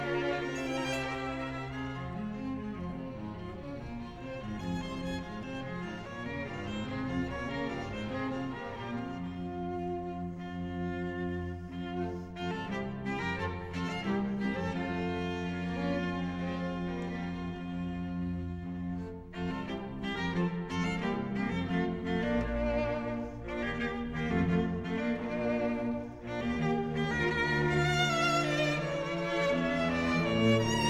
String quartet Nʻ3, op. 67, si bémol majeur